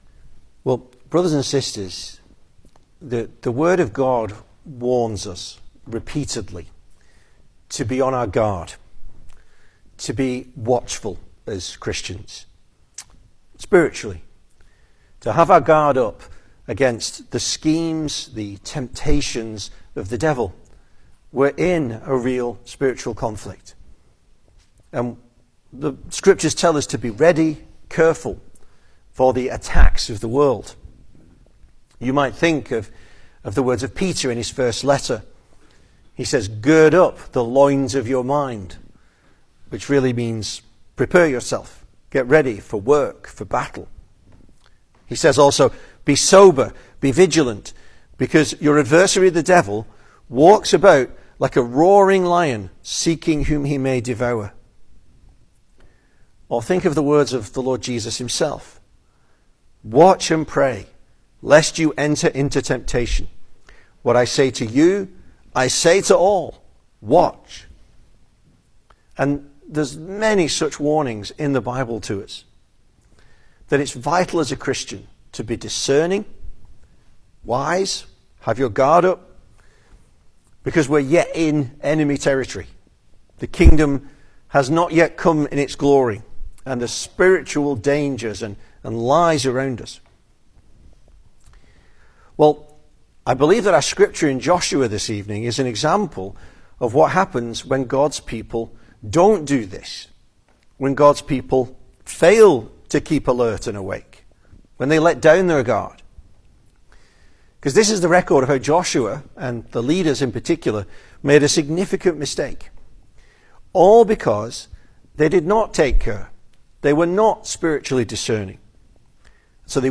2026 Service Type: Sunday Evening Speaker